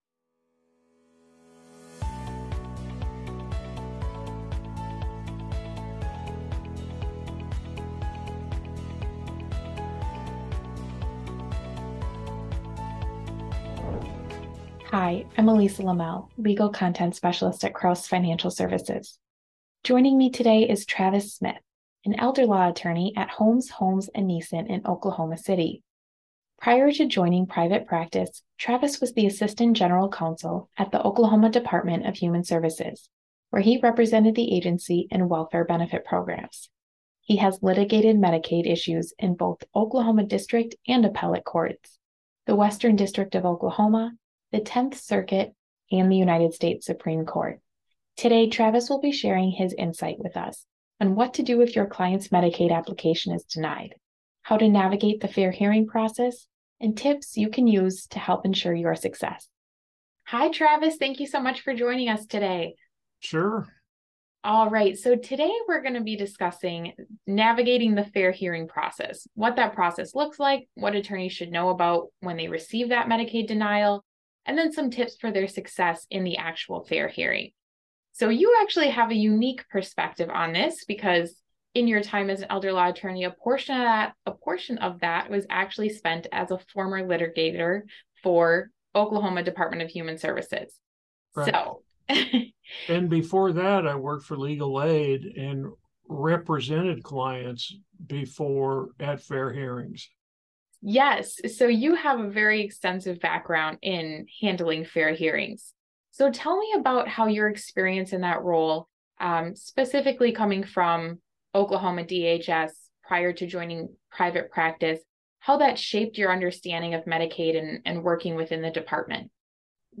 Download Audio Version In this Elder Law Interview